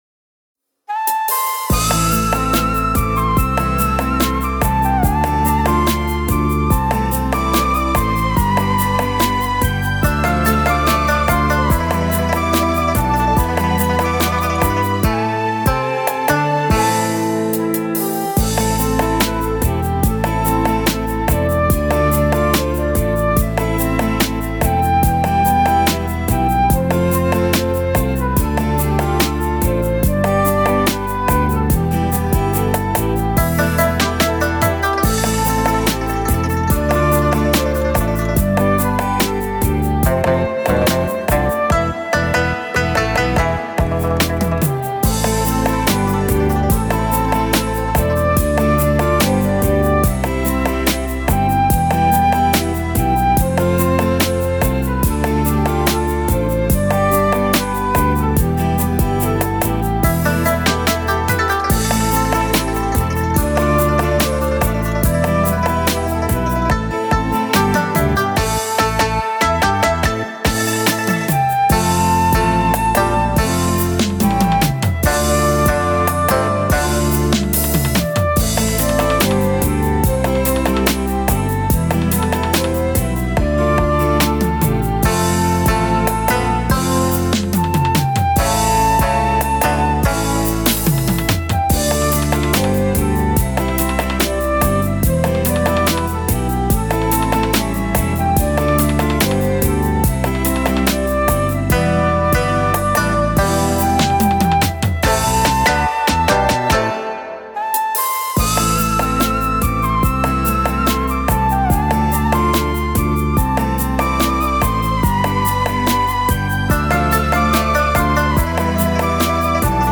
•   Beat  01.
KARAOKE